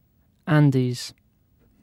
Ääntäminen
Ääntäminen UK Tuntematon aksentti: IPA : /ˈændiːz/ Haettu sana löytyi näillä lähdekielillä: englanti Käännös Erisnimet 1. die Anden Määritelmät Erisnimet A mountain range in western South America .